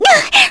Epis-Vox_Damage_kr_01.wav